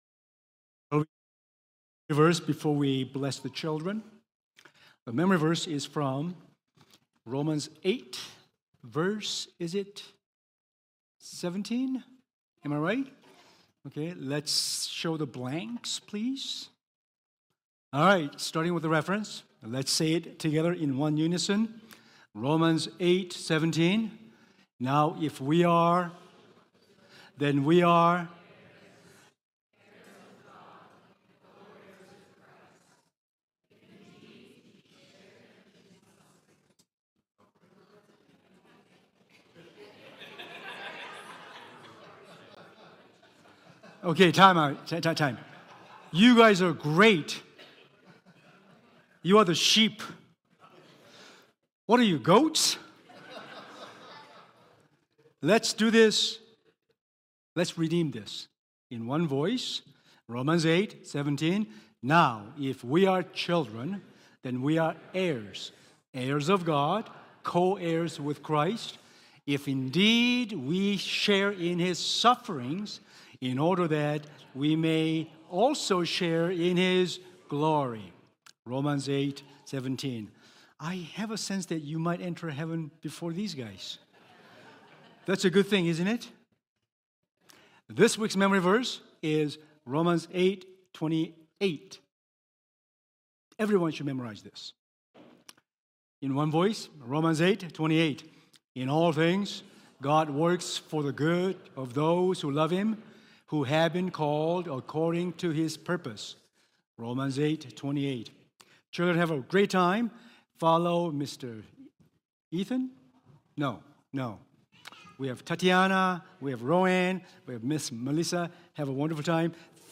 Sermons | mosaicHouse